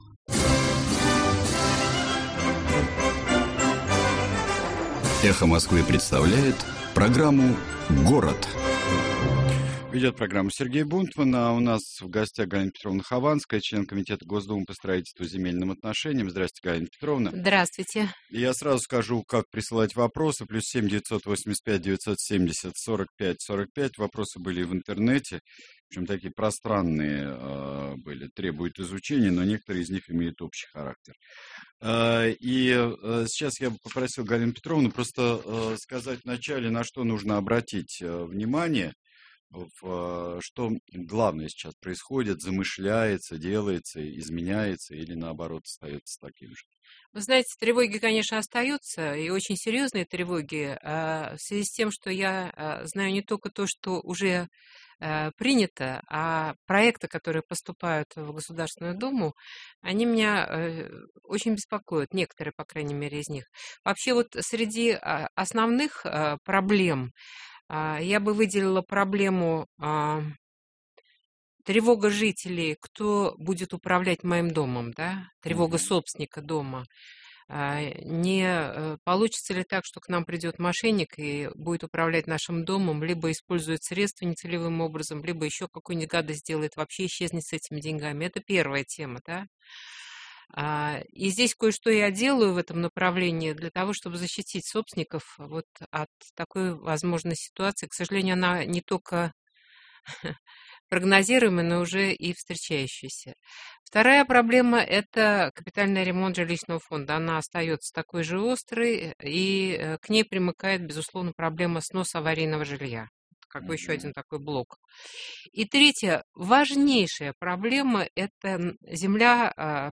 Конференция со слушателями - Галина Хованская - Город - 2008-04-05